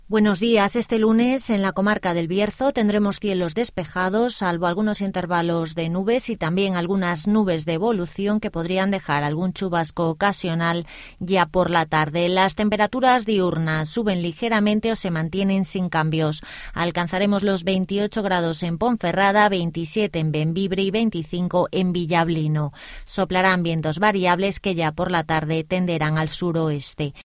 INFORMATIVOS BIERZO
-Conocemos las noticias de las últimas horas de nuestra comarca, con las voces de los protagonistas